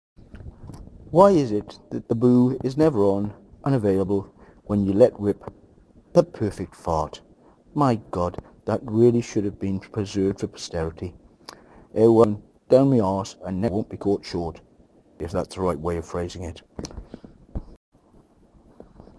Fart